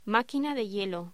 Locución: Máquina de hielo
voz